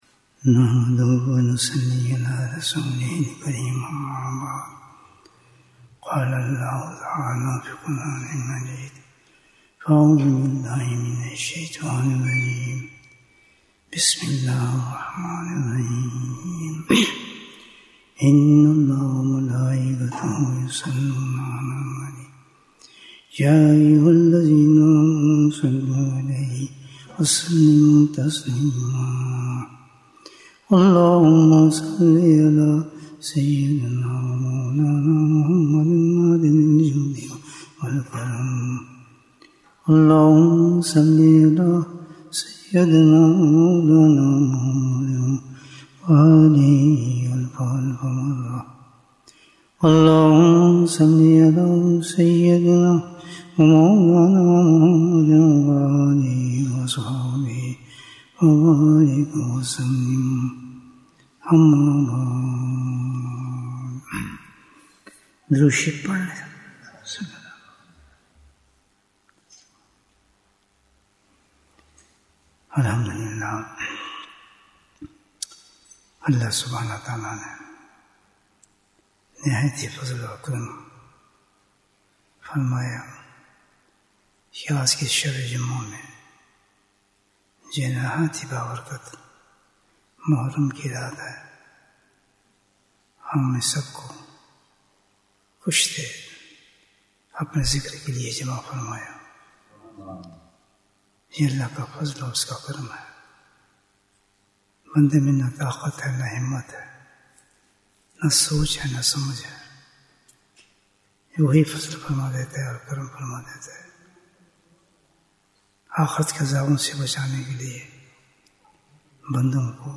Bayan, 45 minutes 3rd July, 2025 Click for English Download Audio Comments What is the Most Dangerous Thing We Must Save Ourselves From?